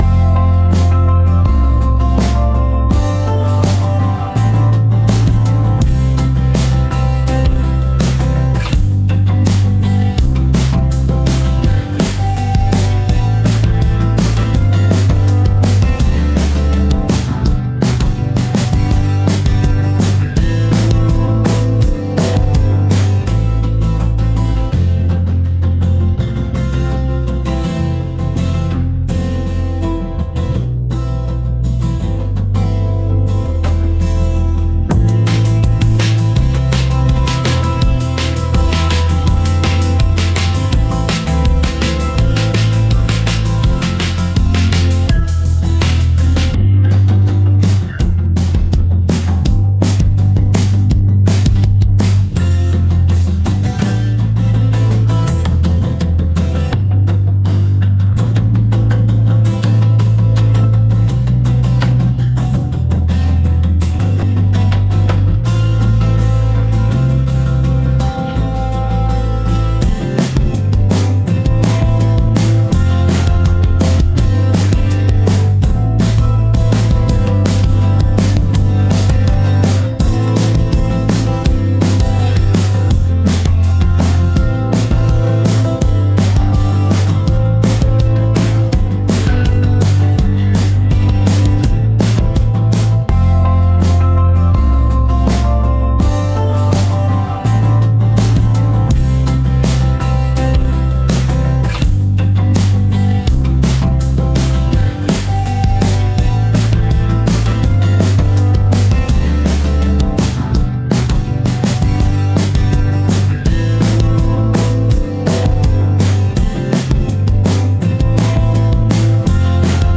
播放器为纯音乐